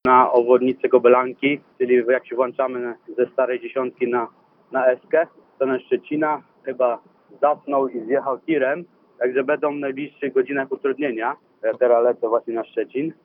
Jak informuje nasz słuchacz, ruch w tym miejscu odbywa się jeszcze w miarę płynnie, ale powoli.